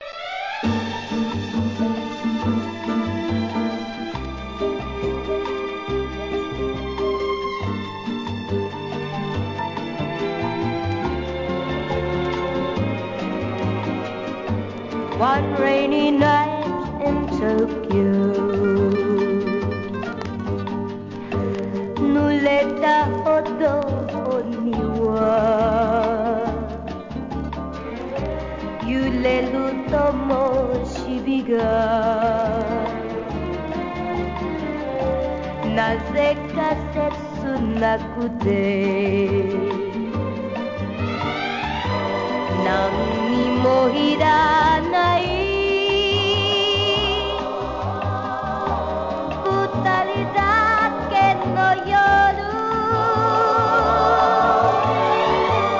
¥ 550 税込 関連カテゴリ SOUL/FUNK/etc...